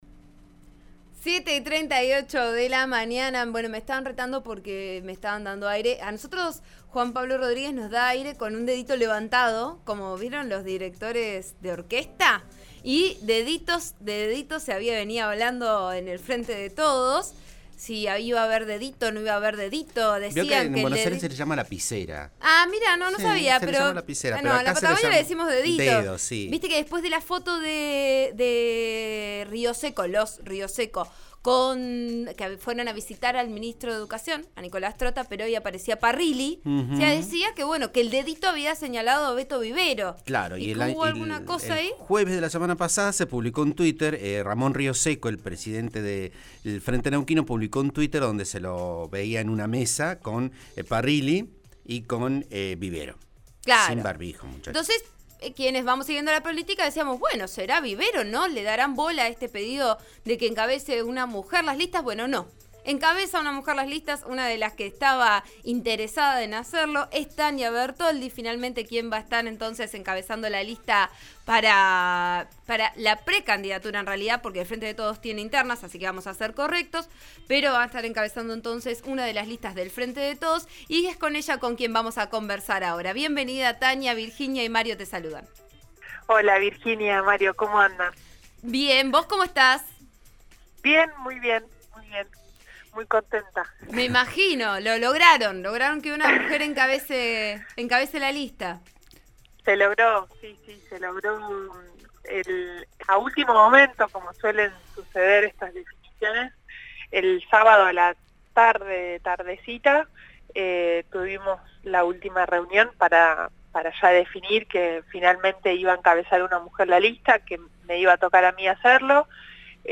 Bertoldi, en diálogo con «Vos A Diario» (RN RADIO 89.3) indicó que el primer paso fue ponerse de acuerdo con sus compañeras del PJ que también querían ser candidatas.